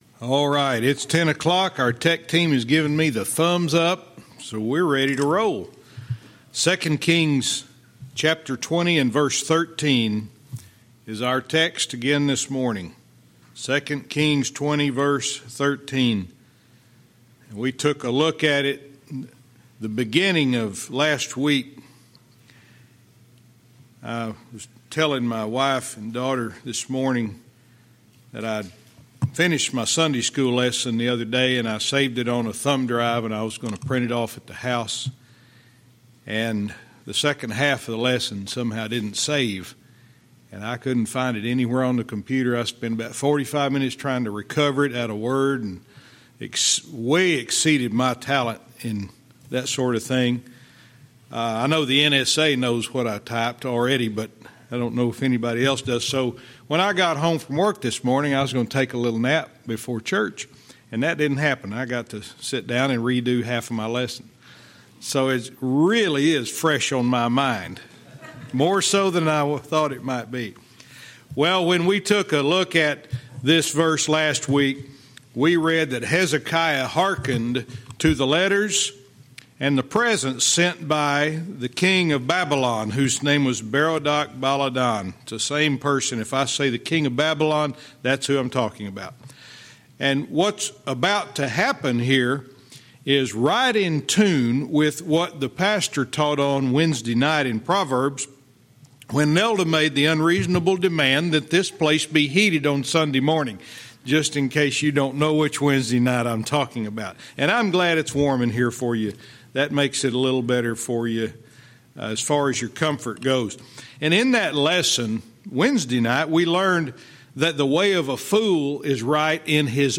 Verse by verse teaching - 2 Kings 20:13(cont)